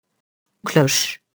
cloche [klɔʃ]